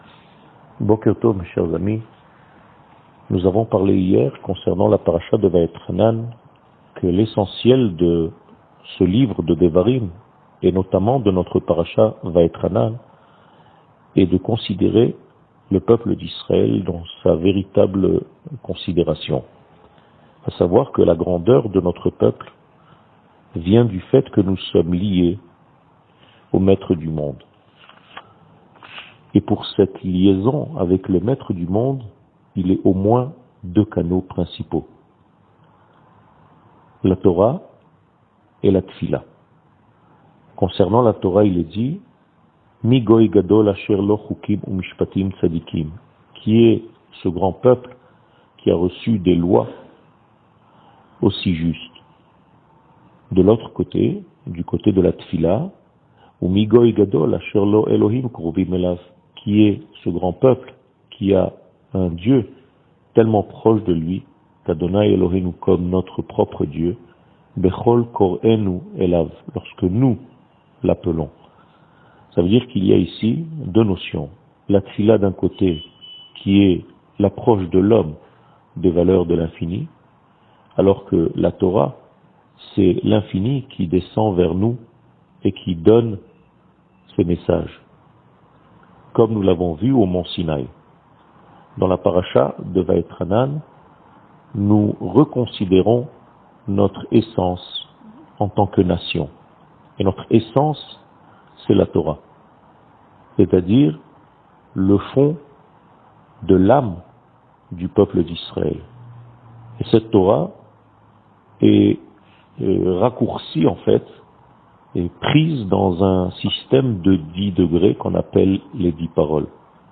שיעור מ 20 יולי 2021